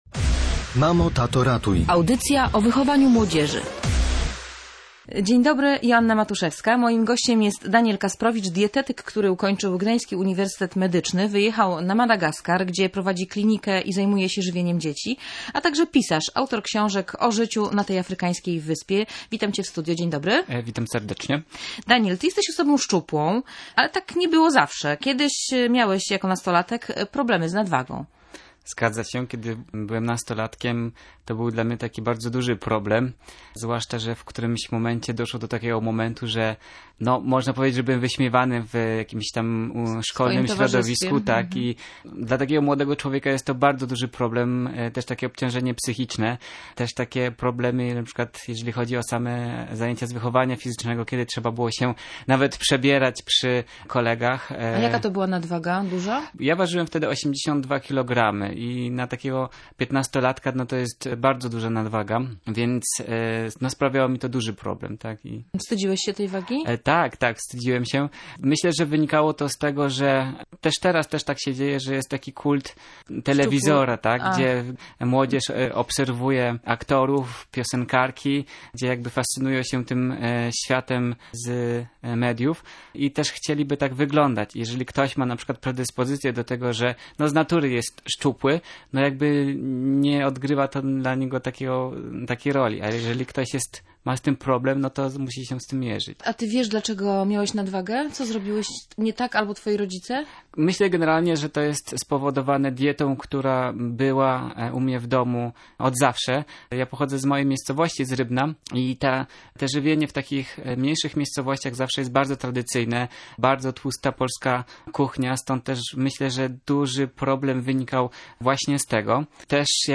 Nastolatek z problemem nadwagi potrzebuje wsparcia najbliższych, by pokonać otyłość – mówił w Radiu Gdańsk dietetyk